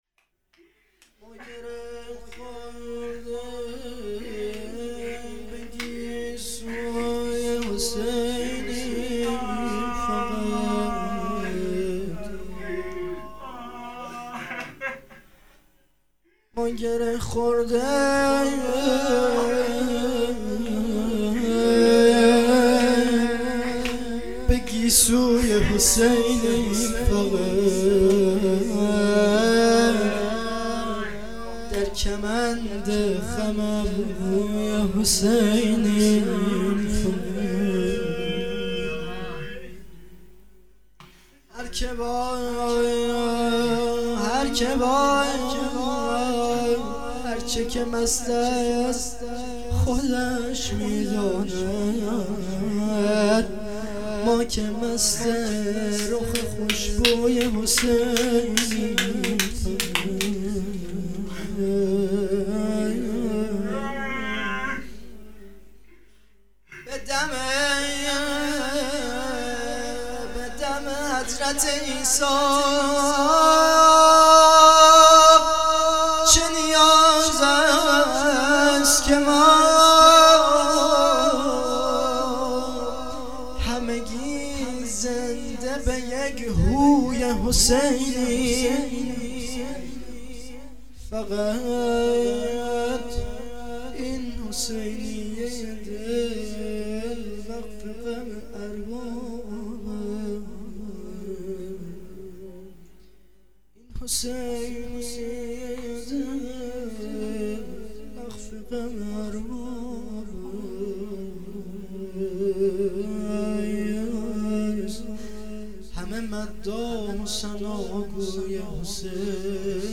شهادت امام هادی ۸-۱۲-۹۸